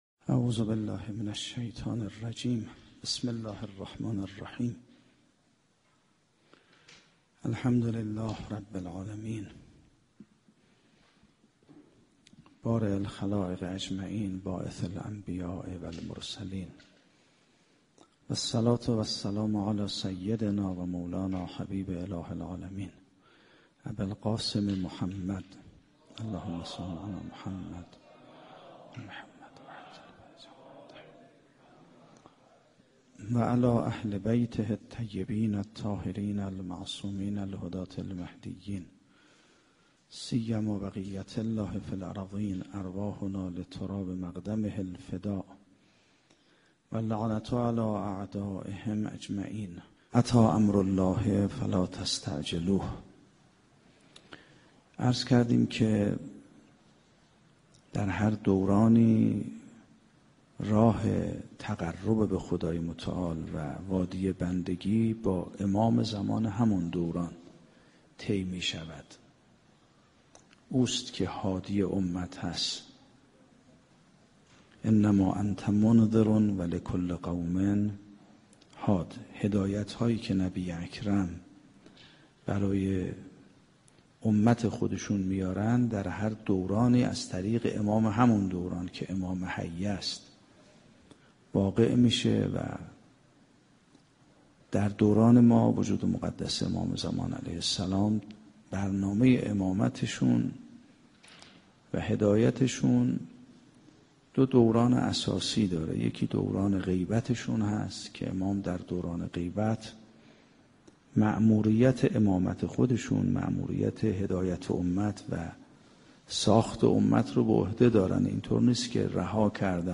سخنرانی آیت الله سیدمحمدمهدی میرباقری با موضوع اجابت ندای دعوت الهی - 8 جلسه